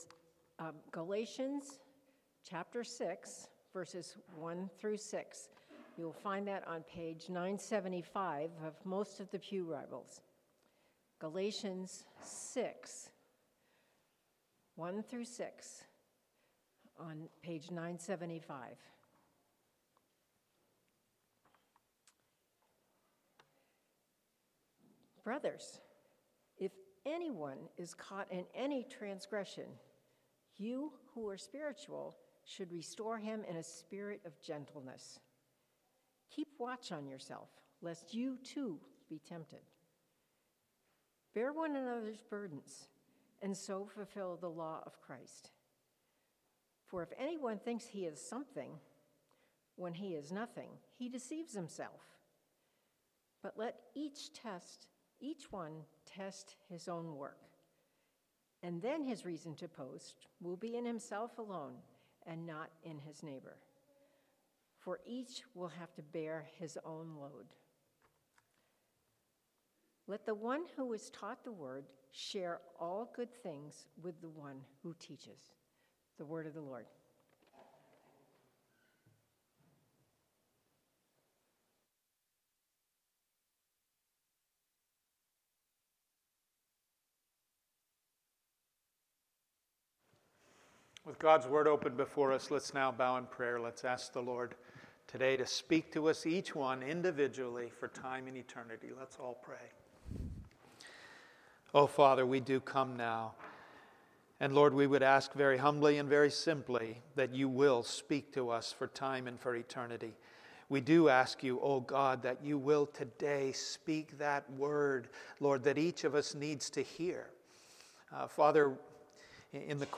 Passage: Galatians 6:1-6 Sermon